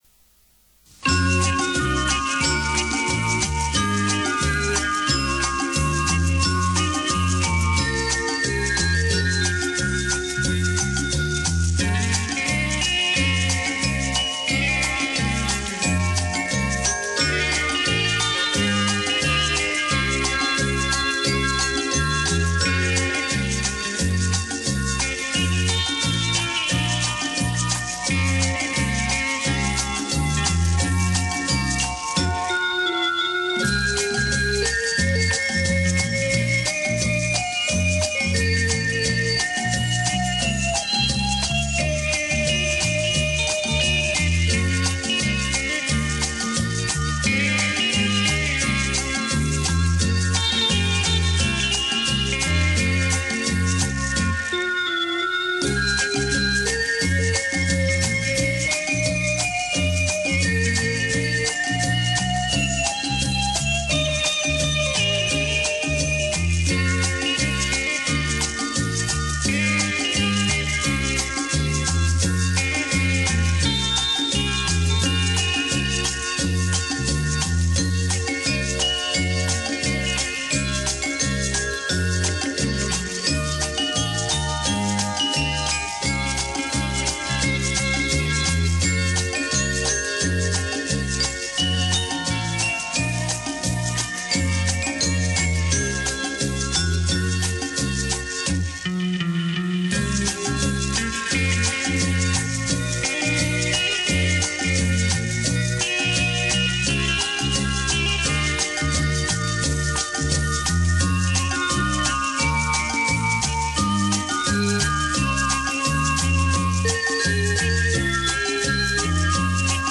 因第一遍国产机器没录好所以现在再好的机器也不能恢复到原始带的水平。